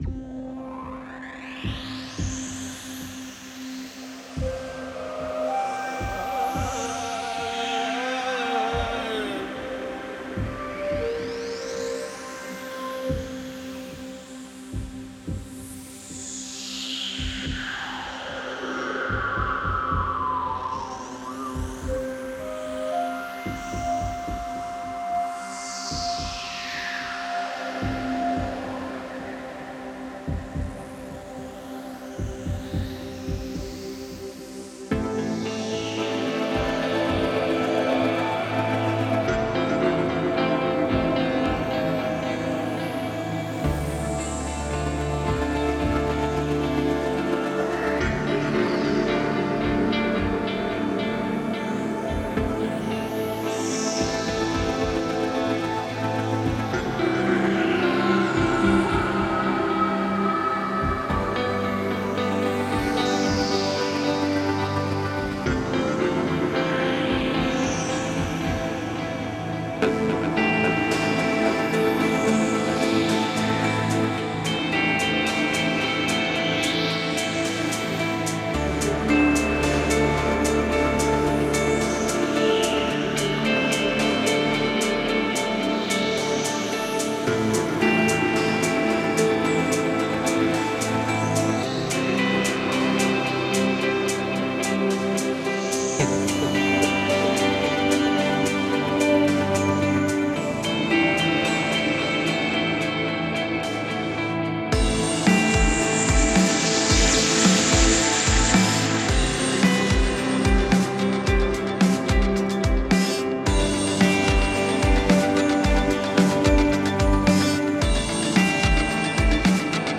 Genre: IDM, Electronic.